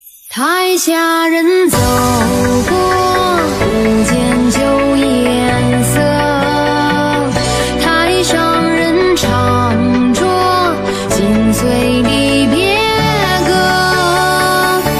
• BPM：129.2（每分钟节拍数）